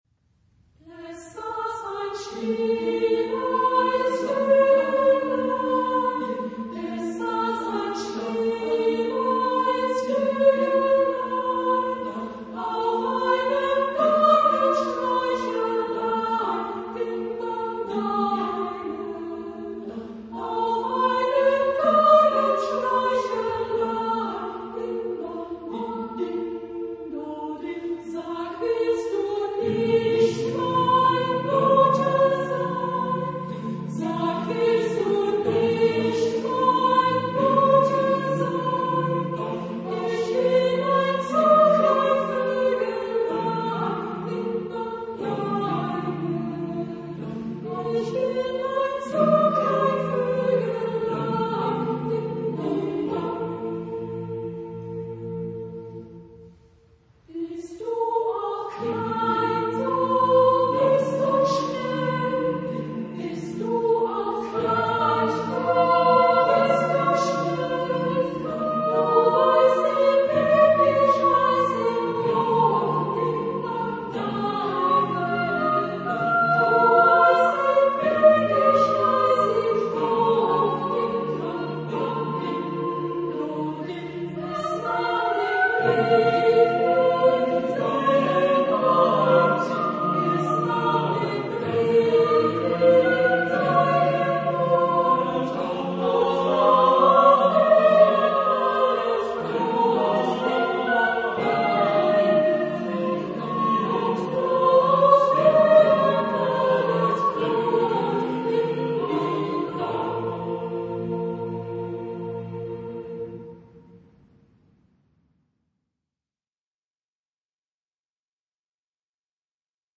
Genre-Stil-Form: Volkslied ; Madrigal ; weltlich
Chorgattung: SAATTBB  (7 Männerchor + Frauenchor Stimmen )
Tonart(en): a-moll